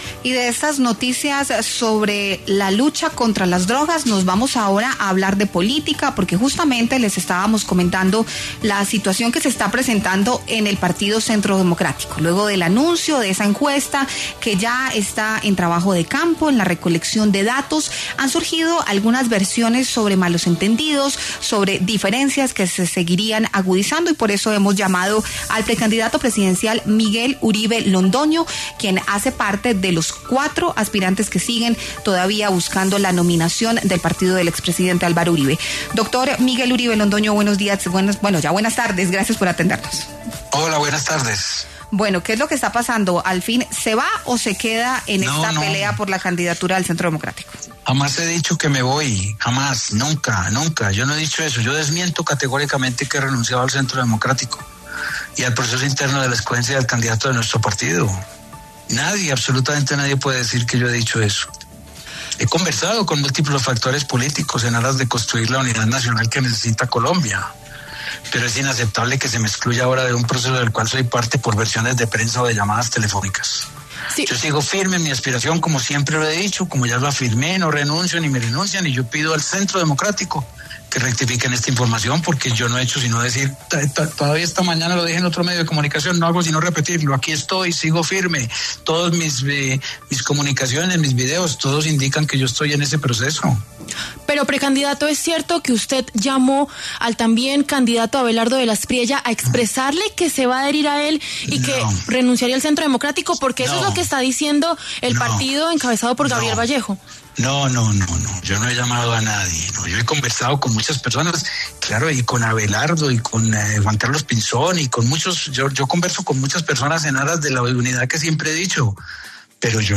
Miguel Uribe Londoño aseveró en W Radio: “Sigo firme en mi aspiración y pido al Centro Democrático que rectifique”.